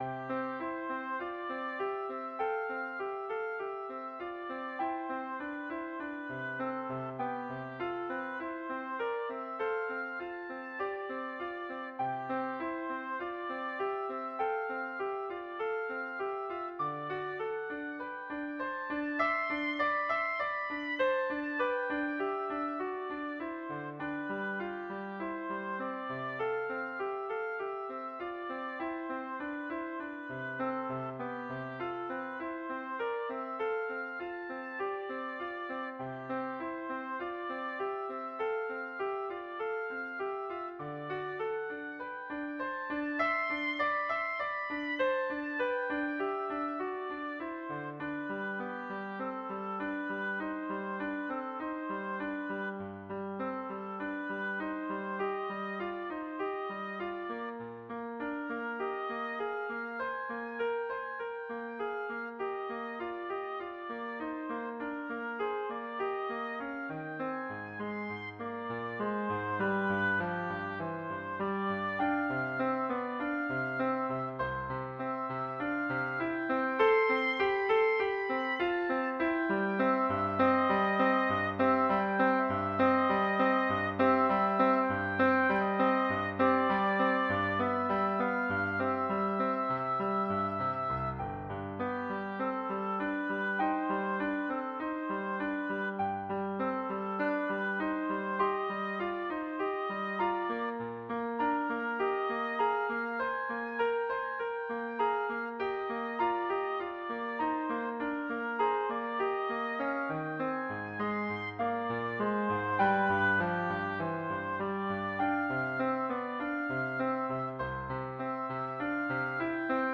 Oboe version
4/4 (View more 4/4 Music)
C major (Sounding Pitch) (View more C major Music for Oboe )
Classical (View more Classical Oboe Music)